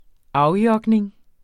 Udtale [ -ˌjʌgneŋ ]